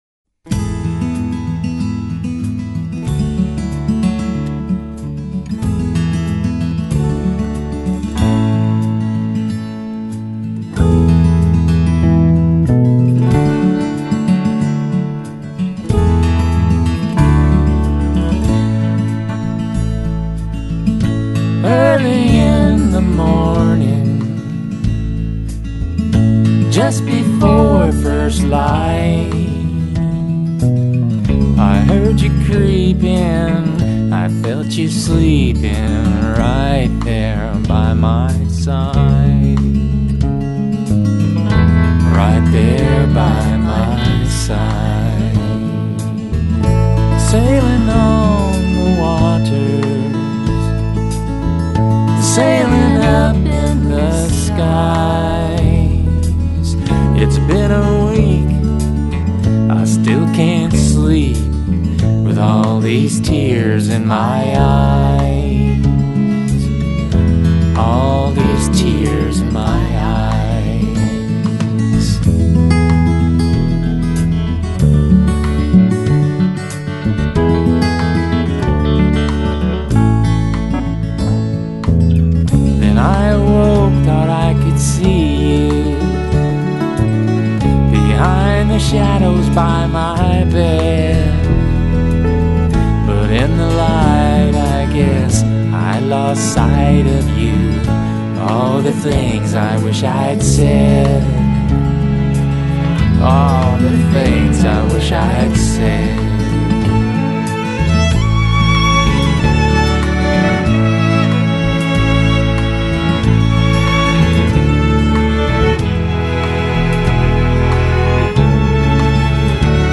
A fine CD recorded in Austin with the best session players.